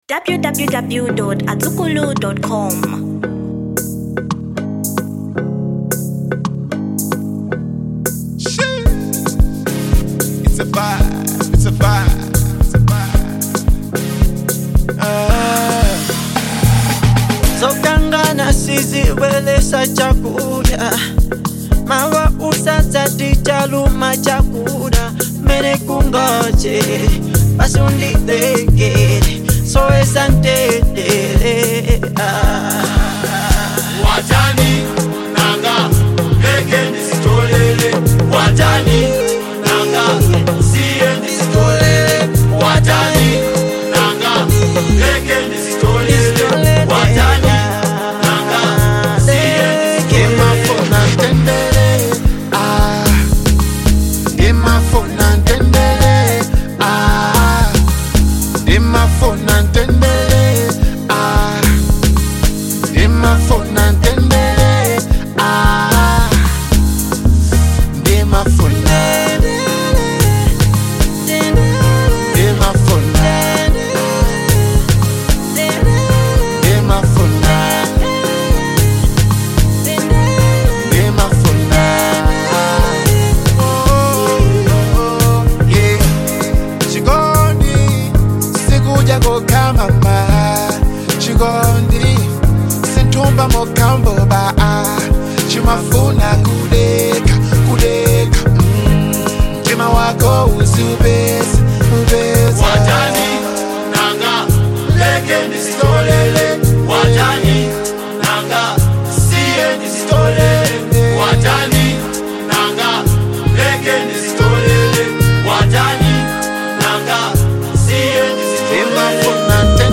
Genre Afrobeats